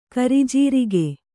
♪ karijīrige